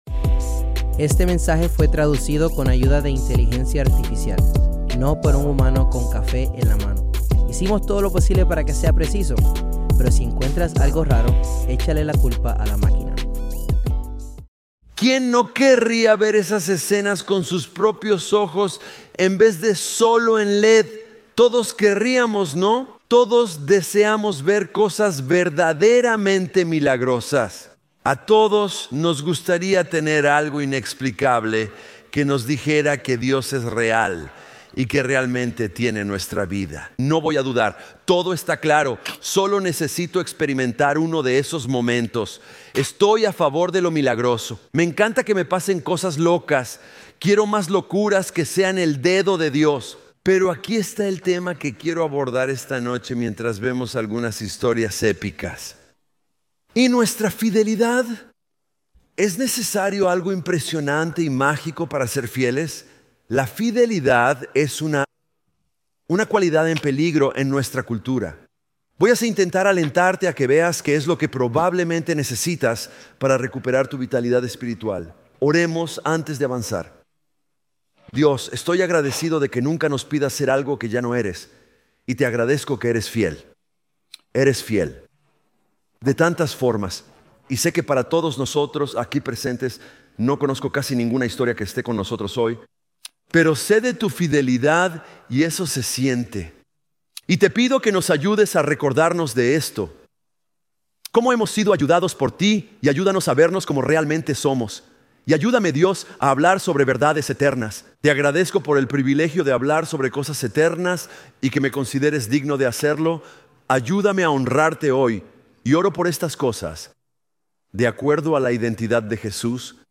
Grabado en vivo en Crossroads Church en Cincinnati, Ohio.